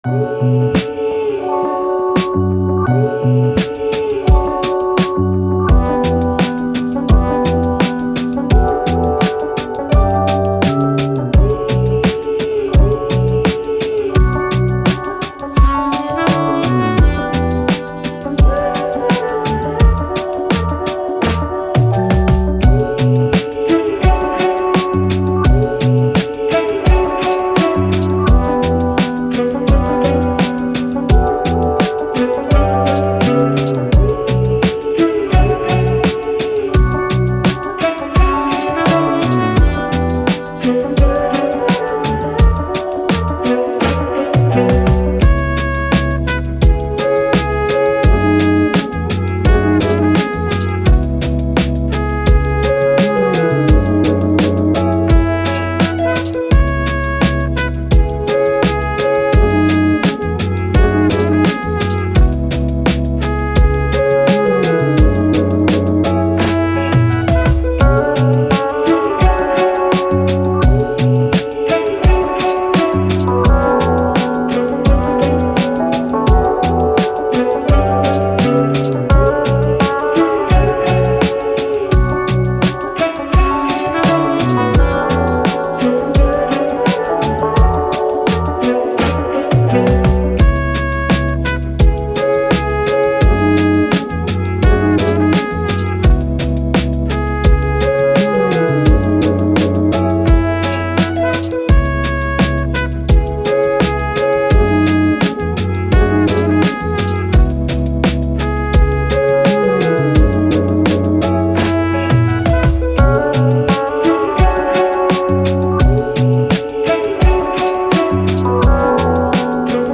Hip Hop, Chilled, Vibe, City, Cool